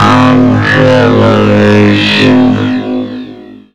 Index of /90_sSampleCDs/Zero-G - Total Drum Bass/Instruments - 3/track64 (Vox EFX)
02-On Television.wav